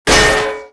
acannonimpactmetala03.wav